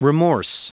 Prononciation du mot remorse en anglais (fichier audio)
Prononciation du mot : remorse